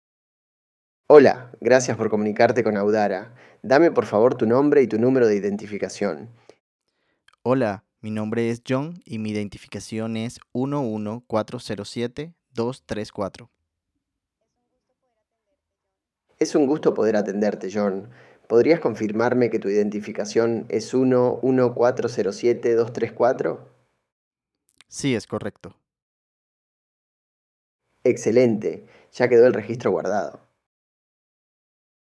A continuación, podrás escuchar ejemplos reales de interacción con nuestros voicebots, en audios donde las voces responden y conversan en tiempo real.
➡ Ejemplo Voicebot: Interacción de un cliente con un Voicebot con acento argentino